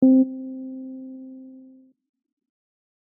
C2.mp3